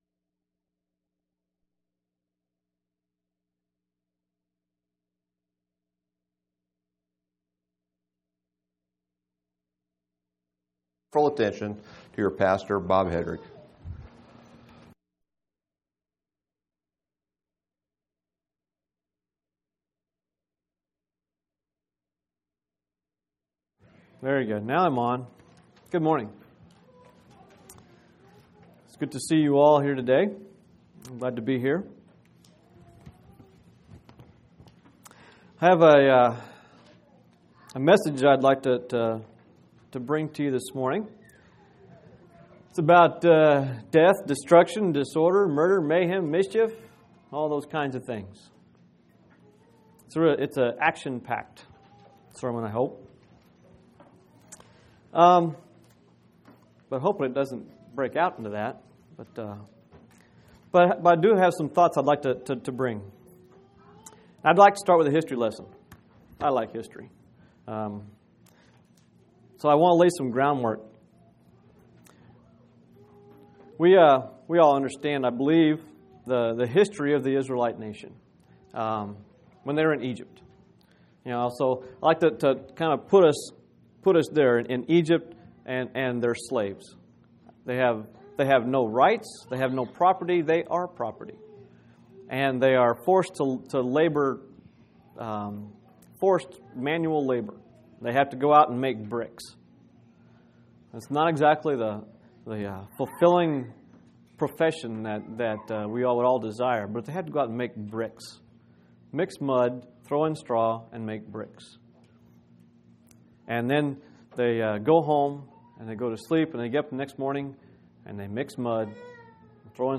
6/26/2005 Location: Phoenix Local Event